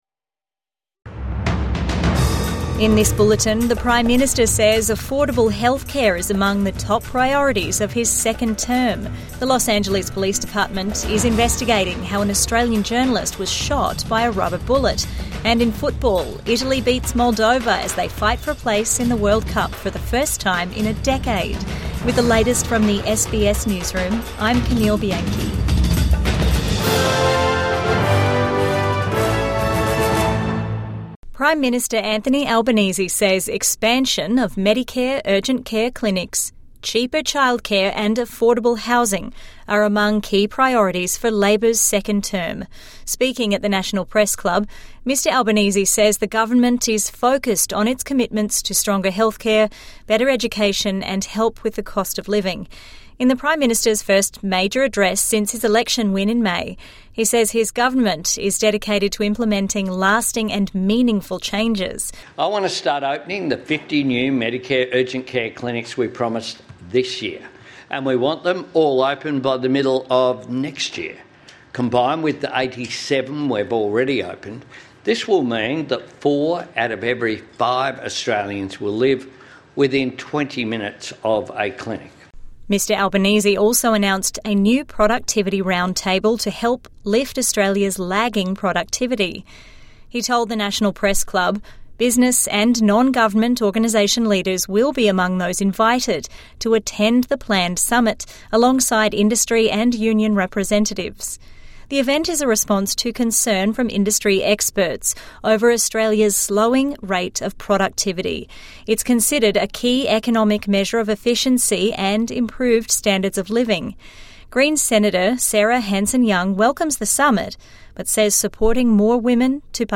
Albanese to focus on health, childcare in second term| Evening News Bulletin 10 June 2025